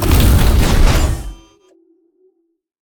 droidic sounds